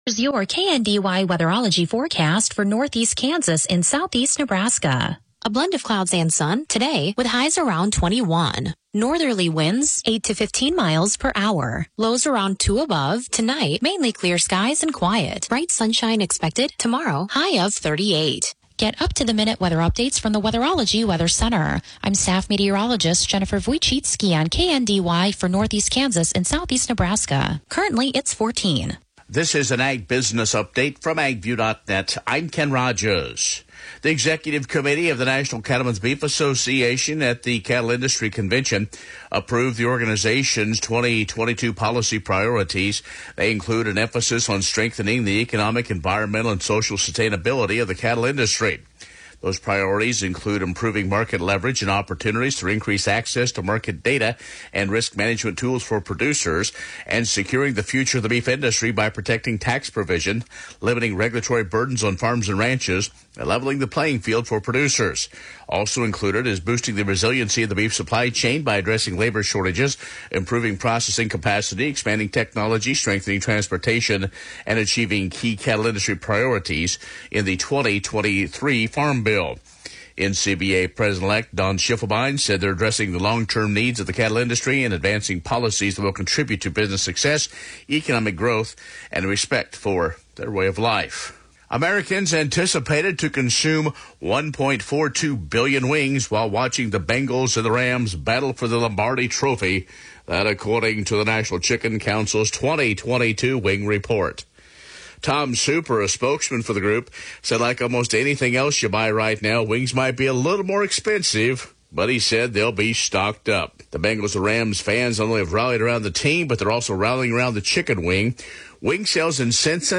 Broadcasts are archived daily as originally broadcast on Classic Country AM 1570/FM 94.1 KNDY.